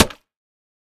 Minecraft Version Minecraft Version 25w18a Latest Release | Latest Snapshot 25w18a / assets / minecraft / sounds / block / mud_bricks / break6.ogg Compare With Compare With Latest Release | Latest Snapshot